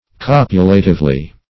Copulatively \Cop"u*la"tive*ly\